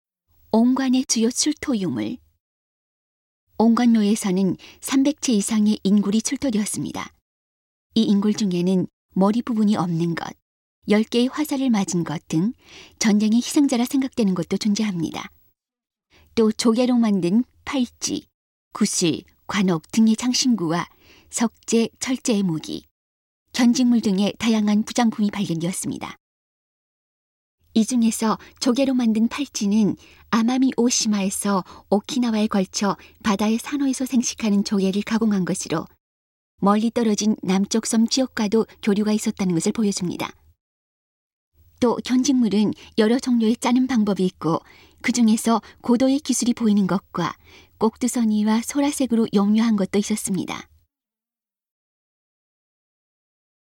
음성 가이드 이전 페이지 다음 페이지 휴대전화 가이드 처음으로 (C)YOSHINOGARI HISTORICAL PARK